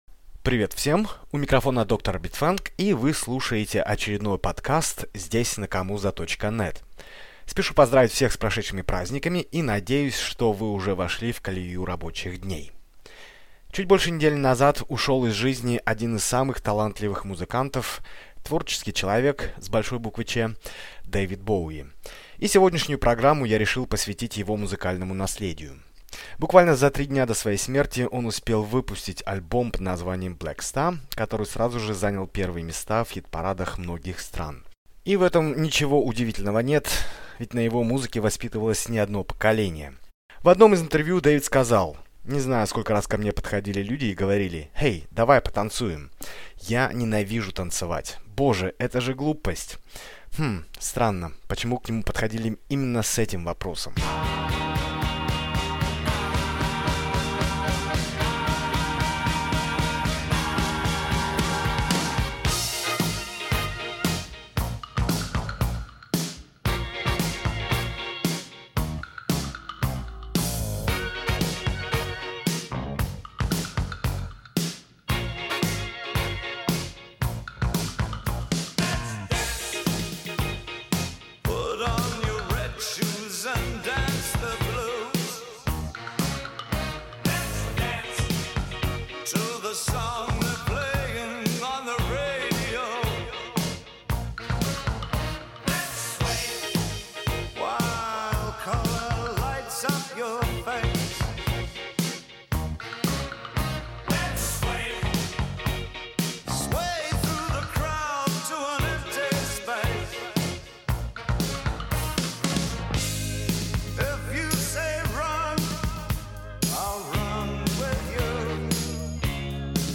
кавер-версий